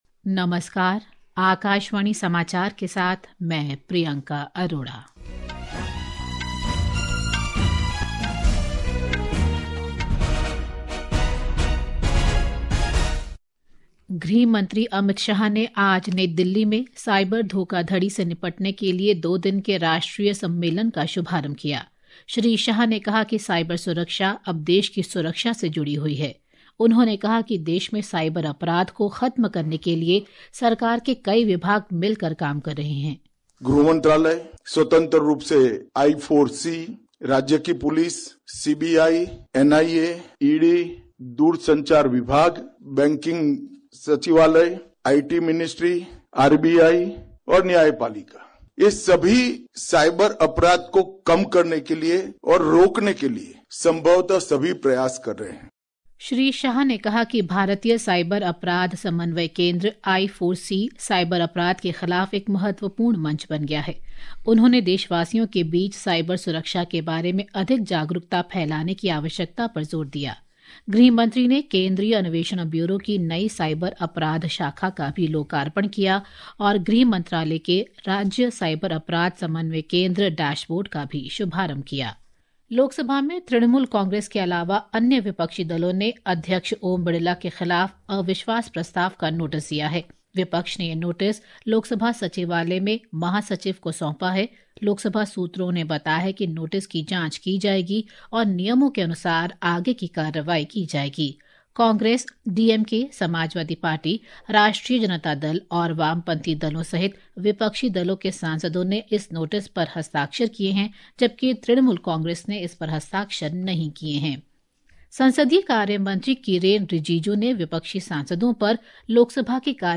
National Bulletins
प्रति घंटा समाचार